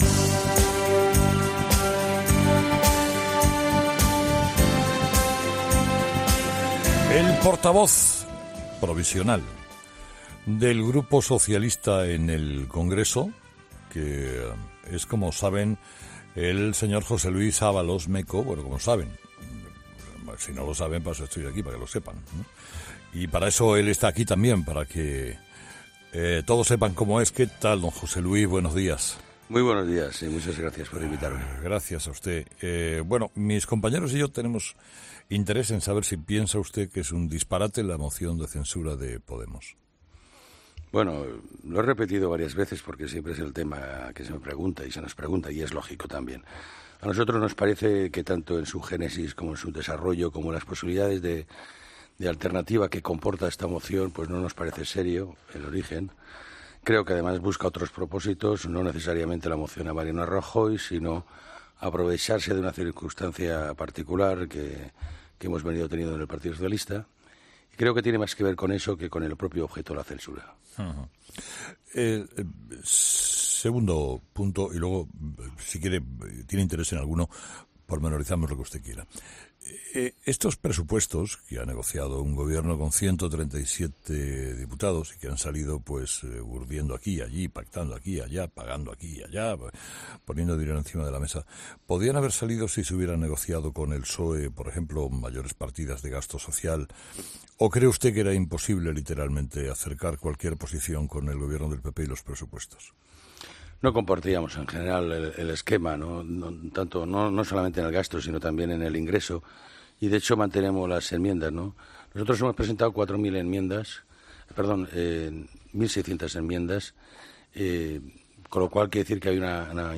Escucha la entrevista completa a José Luis Ábalos, portavoz provisional del PSOE en el Congreso